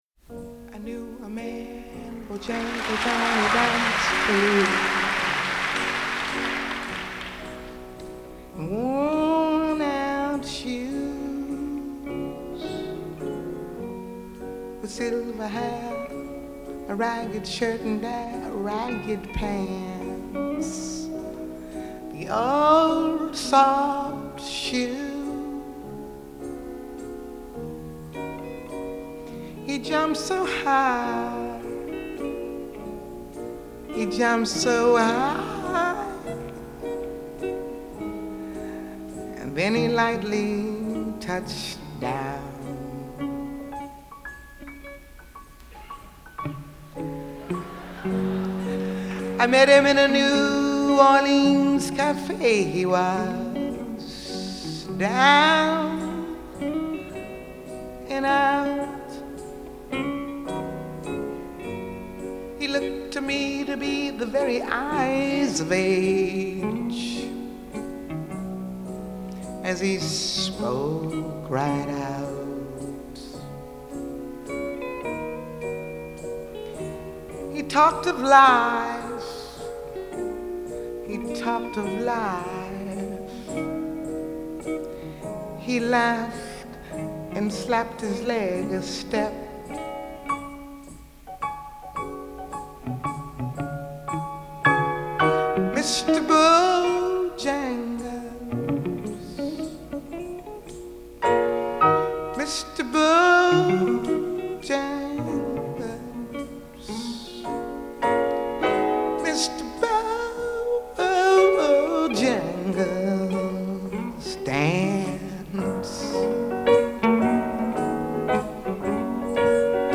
Jazz, Soul, Blues